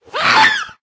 scream3.ogg